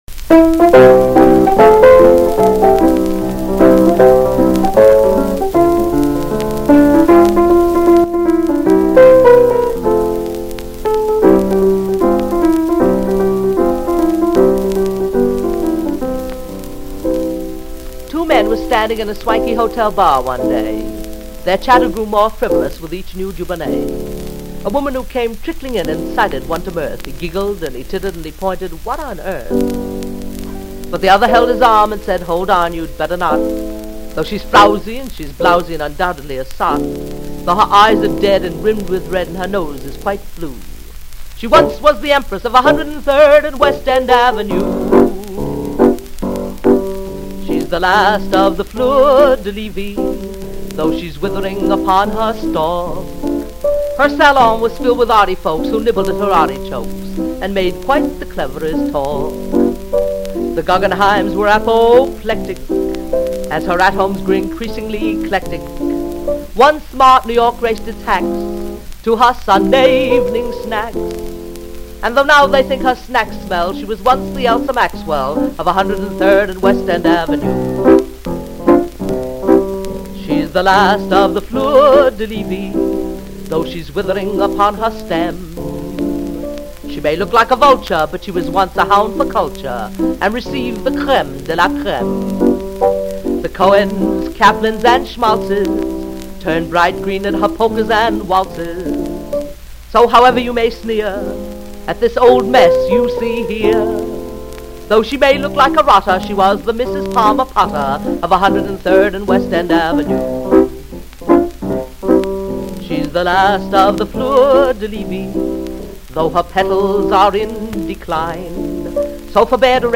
--w. piano, New York City, Oct., 1939 Reeves Sound Studios.